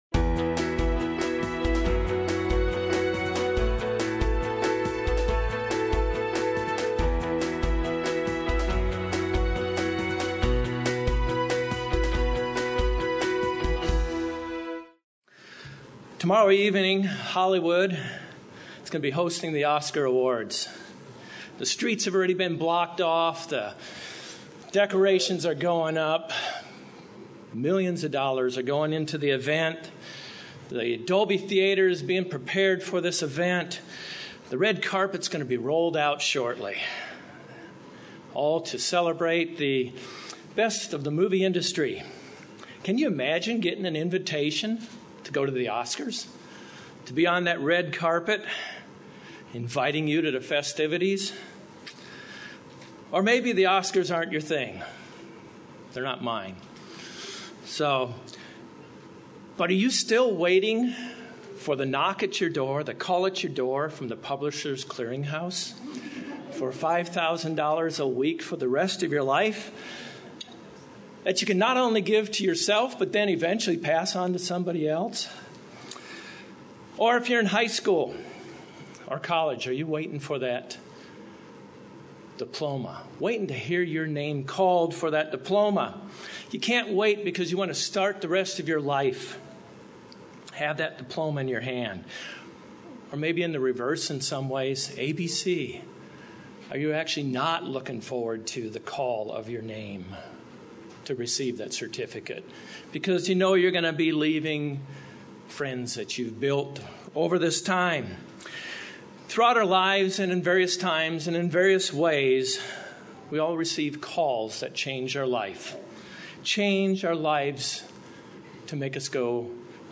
This sermon will examine these topics and show how God's calling is a personal invitation.